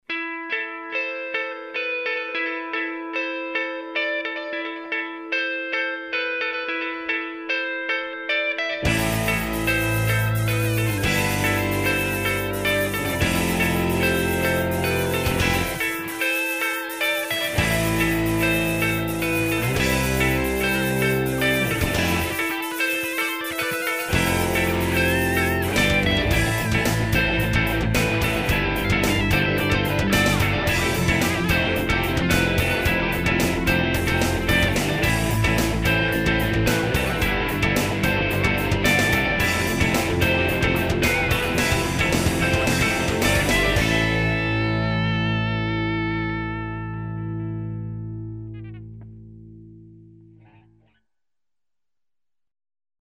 At The End - Built around a delayed guitar sound, with a guitar solo mixed just above the backing tracks.